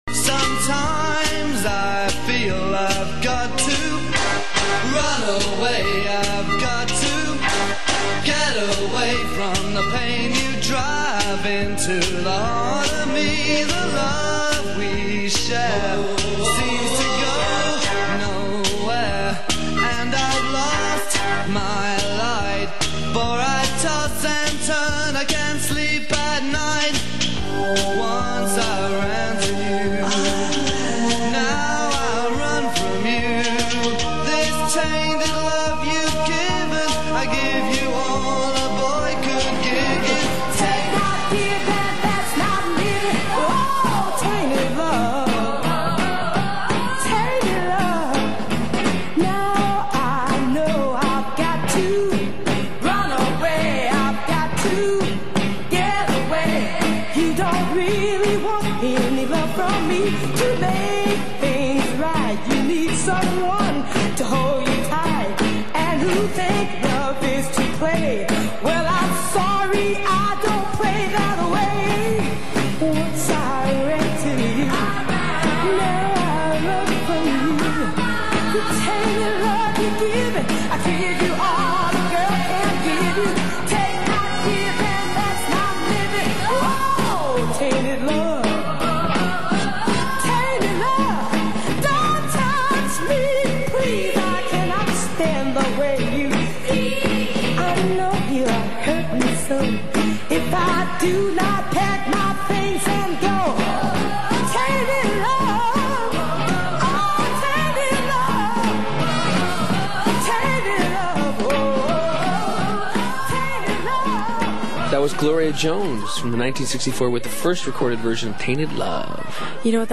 You can stream it here: or just download a relatively low quality version for play elsewhere (I’ve got a better copy here, but it’s A LOT bigger, and this is as good as radio anyway.) Under The Covers – Episode 1 (Pilot) The show has its own section at the CBC’s site, including an episode guide that has lots of interesting details about upcoming shows.